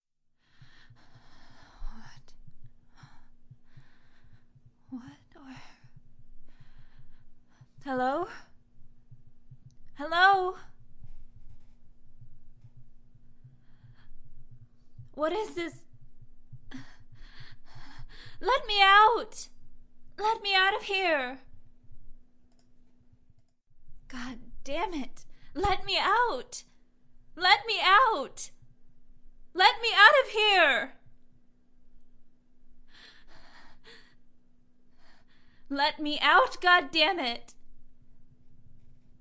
女人的声音 " 害怕的女孩要求2
标签： 语音 女孩 女性 声乐 要求 吓得
声道立体声